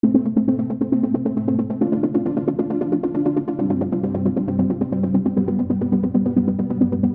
琶音和低频
描述：低切的arp riff，带有慢速攻击的滤波器包络。
Tag: 135 bpm Trance Loops Synth Loops 1.20 MB wav Key : Unknown